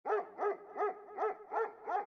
dog3.mp3